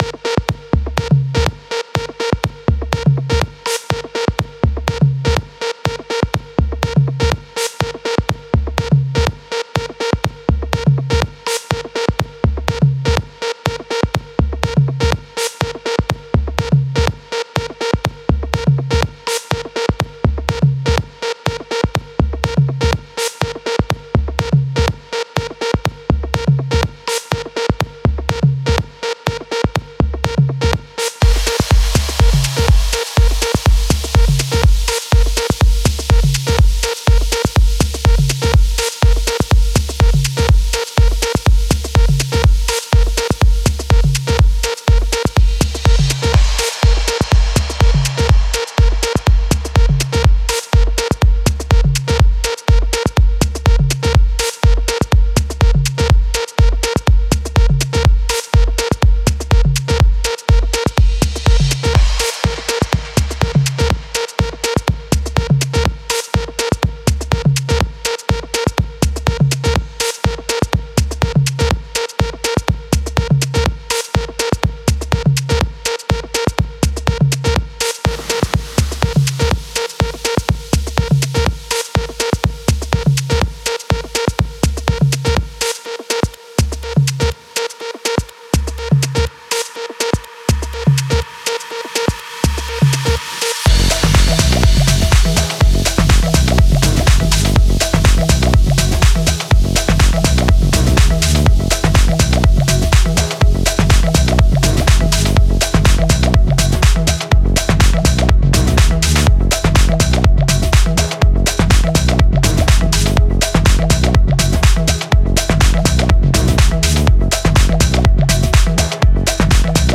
Genre Downbeat